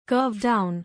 curve-down.mp3